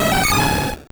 Cri de Scarhino dans Pokémon Or et Argent.